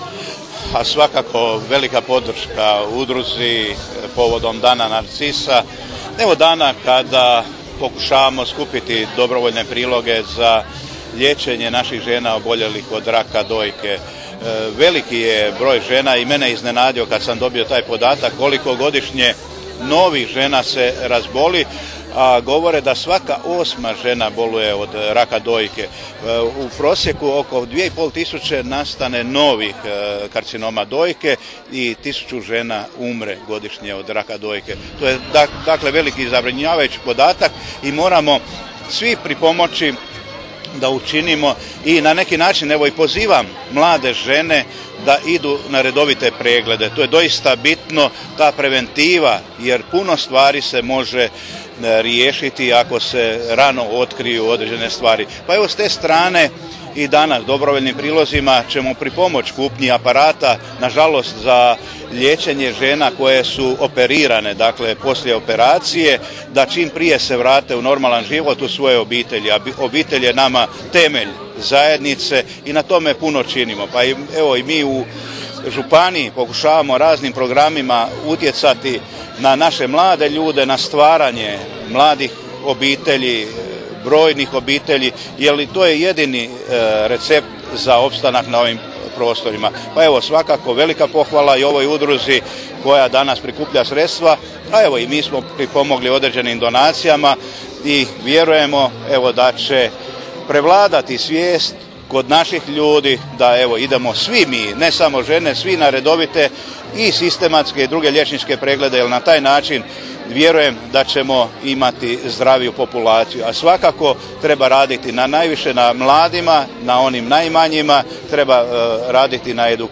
Svoju podršku Udruzi žena liječenih od bolesti dojke – Sisak župan je iskazao u izjavi koju možete poslušati ovdje: